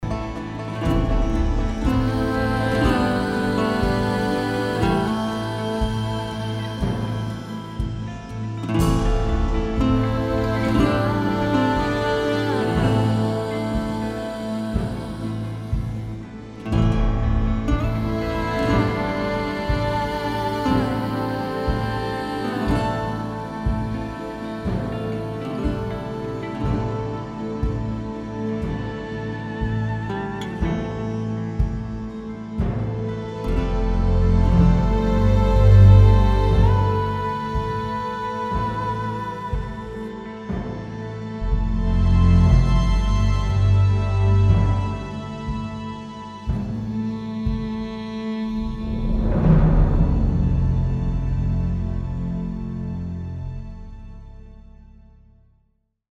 Energetic Soaring Ahs
Vocalise